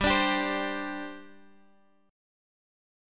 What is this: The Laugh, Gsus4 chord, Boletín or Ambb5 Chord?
Gsus4 chord